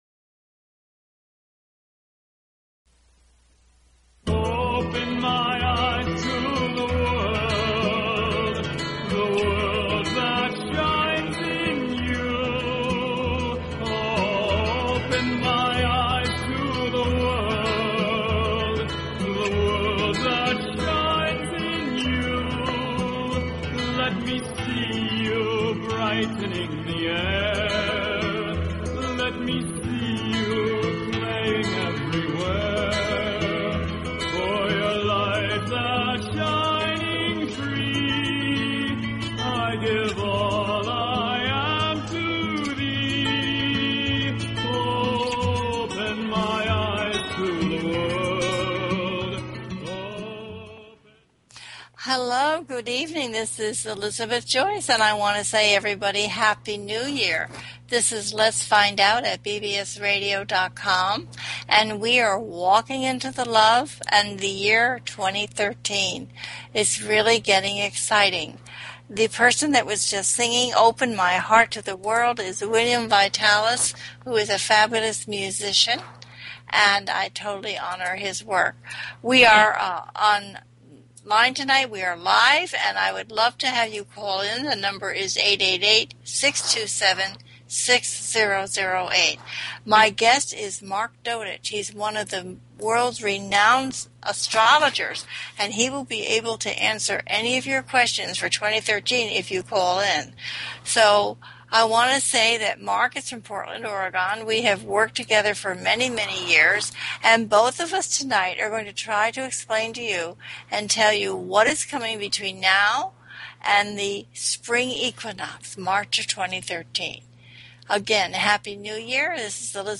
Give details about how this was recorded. THIS IS A CALL IN SHOW, so please get your questions ready and give us a call.